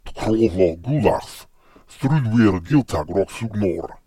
It incorporates a range of vocalizations, alternating between normal voice production and deep, guttural growls, reminiscent of the Fomorians' intimidating presence. Similar to certain languages that feature nasalized or breathy vowels, the Fomorian language exhibits intricate phonetic complexities.